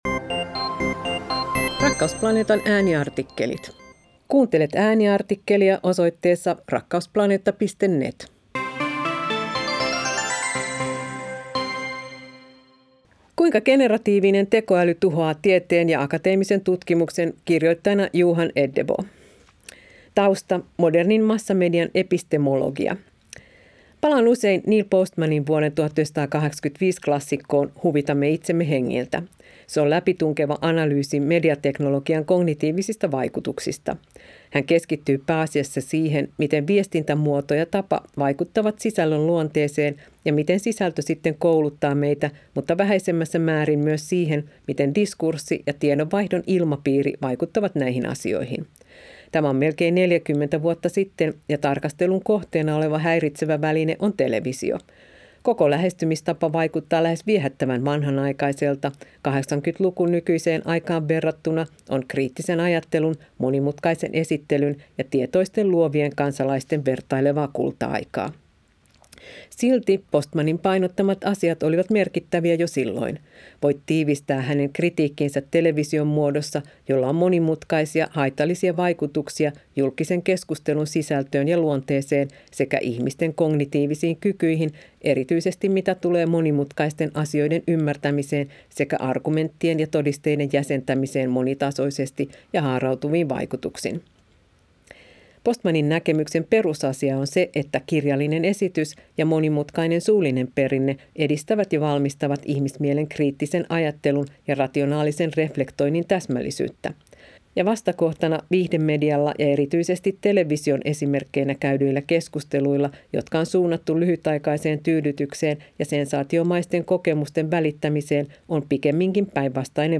Ääniartikkelit